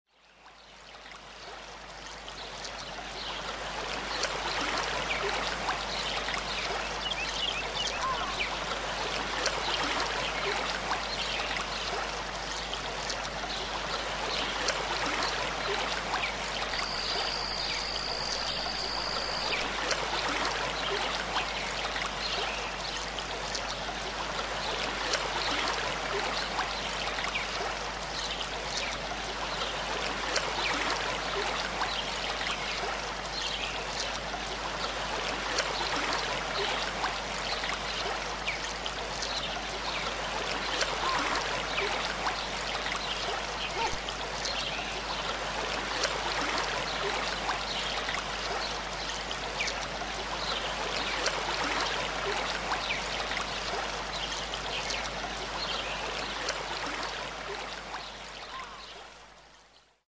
Tranquil Waterfall
Category: Animals/Nature   Right: Personal